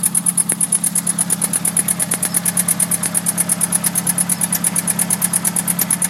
Sujet du message: Bruit moteur
Je voudrais vous soumettre une question : ma voiture fait un bruit lorsque le moteur est chaud, bruit qui varie en fonction du régime moteur (une sorte de « cui-cui »). Ça le fait aussi bien en roulant que voiture immobile.
Si ,il y a un "cui-cui" caractèristique , c'est vrai peu perceptible.
bruit_traction.mp3